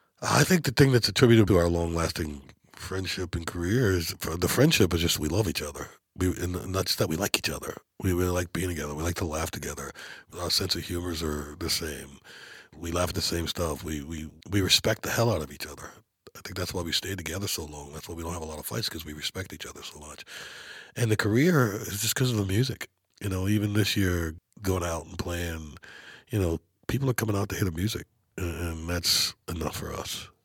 Darius Rucker talks about what contributes to the long friendship and working relationship between his fellow members of Hootie & The Blowfish.